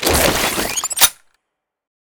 wav / general / combat / weapons / generic rifle / draw1.wav
draw1.wav